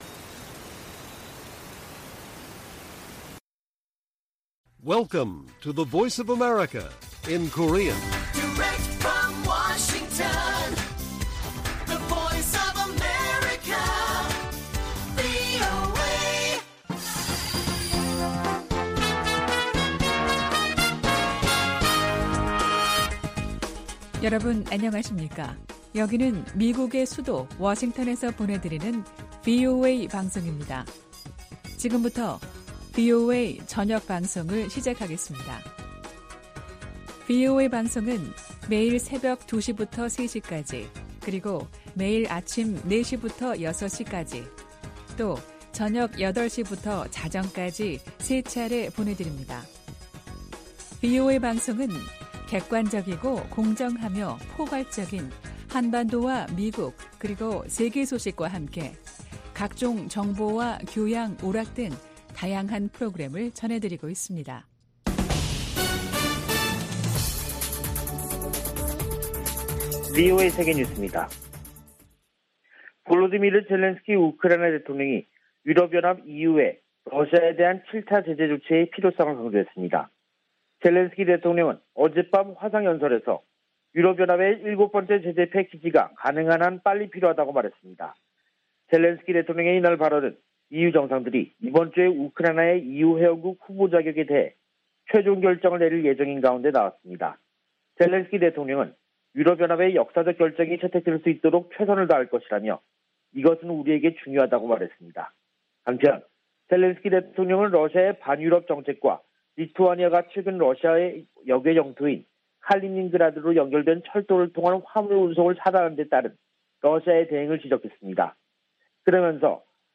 VOA 한국어 간판 뉴스 프로그램 '뉴스 투데이', 2022년 6월 22일 1부 방송입니다. 미 국무부가 미한 상호방위 역량 강화와 대북 대화 추구 등 주한 미국대사관의 향후 4년 목표를 공개했습니다. 미국 정부가 한반도를 제외한 모든 지역에서 대인지뢰 사용과 생산 등을 금지한다고 밝혔습니다. 미 국방부가 북한의 탄도미사일 대응에 적합하다는 평가를 받고 있는 해상요격기 SM-3 미사일 생산 업체를 선정했습니다.